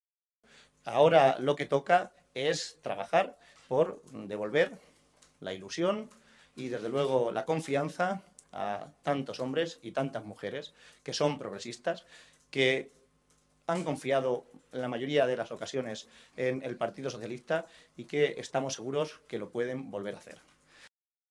Así se expresaba el secretario de Organización regional, José Manuel Caballero, esta tarde en rueda de prensa, tras la celebración de la Ejecutiva regional.